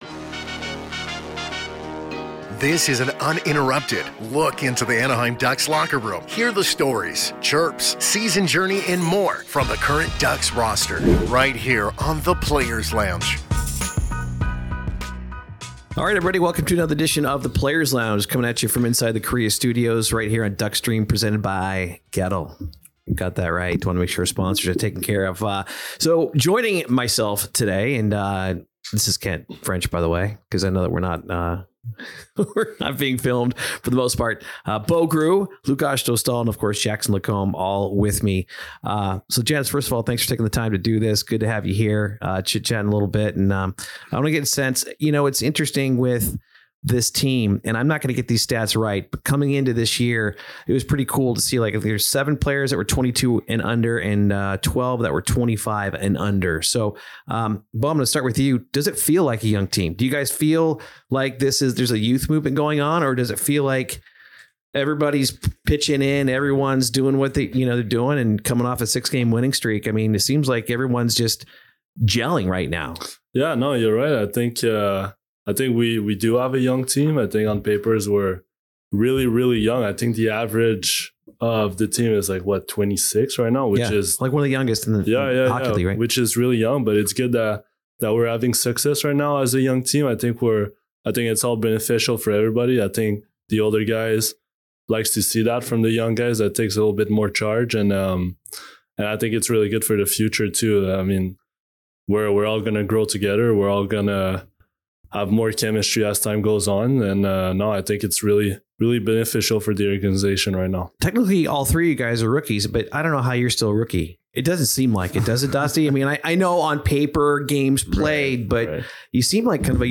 in the Kariya Studio. The guys chat about the current Anaheim Ducks season, rookie pranks, cars and more!